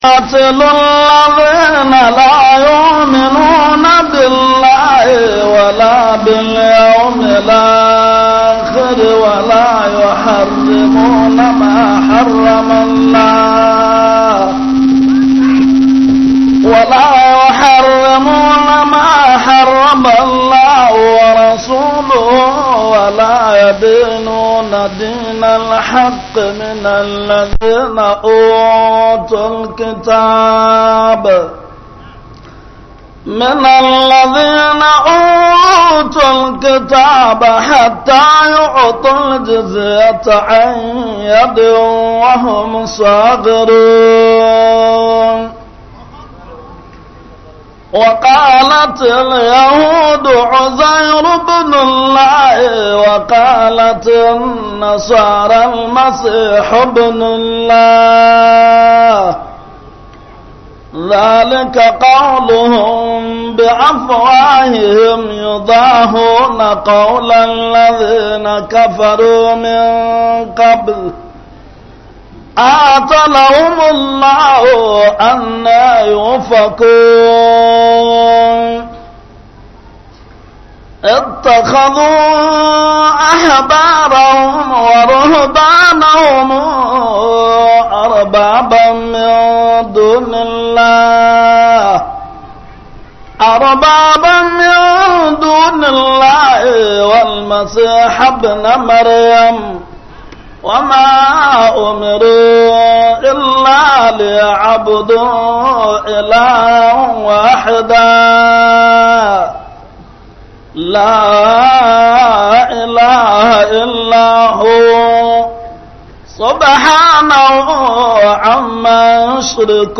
MUHADARA